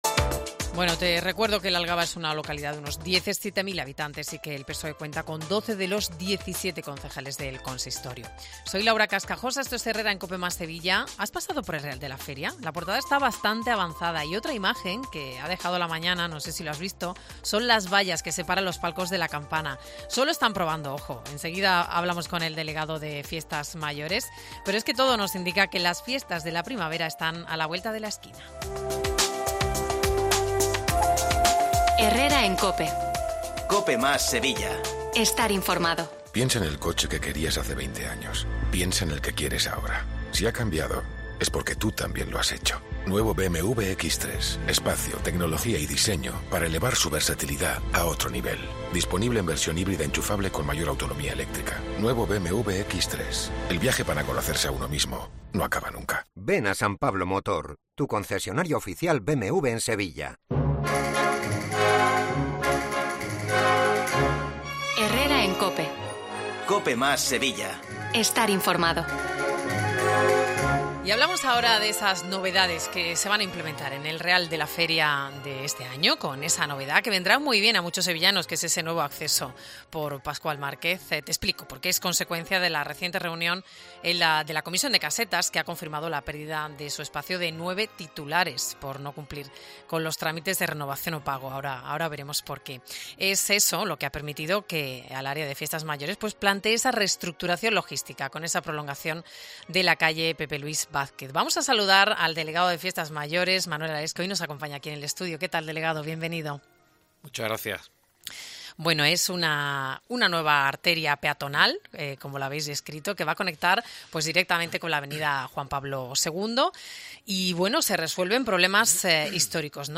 El delegado de Fiestas Mayores, Manuel Alés, cuenta en Herrera en Cope cómo el espacio liberado por esta y otras ocho casetas ha permitido crear un nuevo acceso peatonal en la calle Pascual Márquez
Manuel Alés, Delegado de Fiestas Mayores del Ayuntamiento de Sevilla